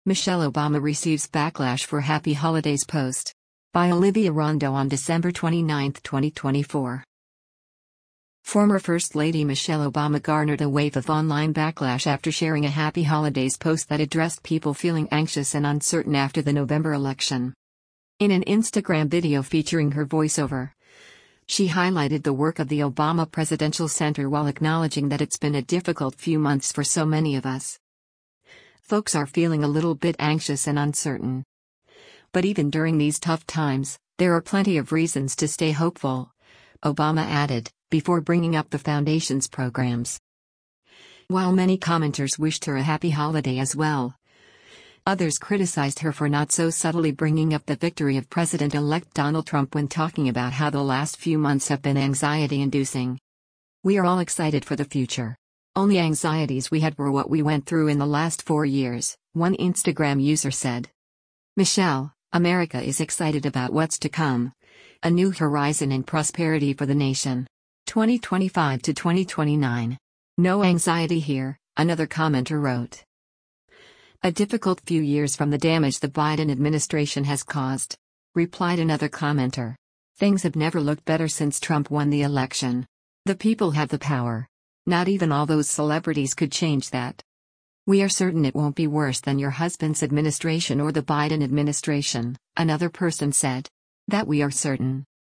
In an Instagram video featuring her voiceover, she highlighted the work of the Obama Presidential Center while acknowledging that “it’s been a difficult few months for so many of us”: